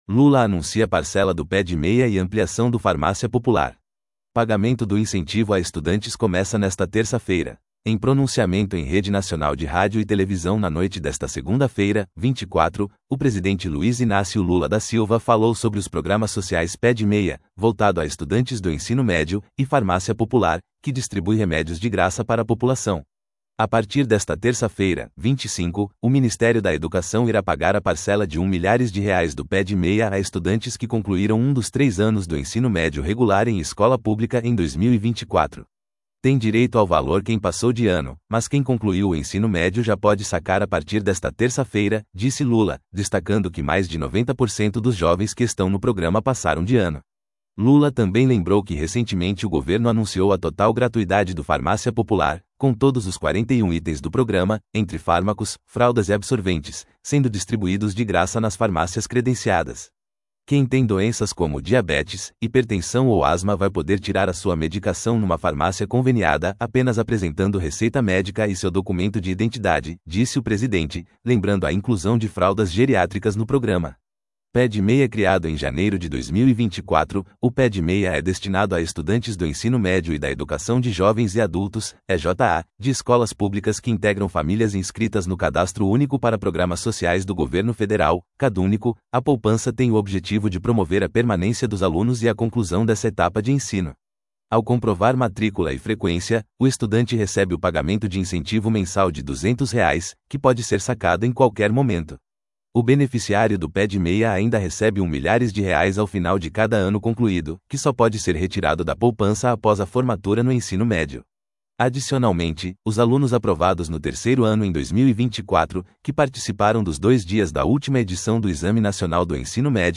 Em pronunciamento em rede nacional de rádio e televisão na noite desta segunda-feira (24), o presidente Luiz Inácio Lula da Silva falou sobre os programas sociais Pé-de-Meia, voltado a estudantes do ensino médio, e Farmácia Popular, que distribui remédios de graça para a população.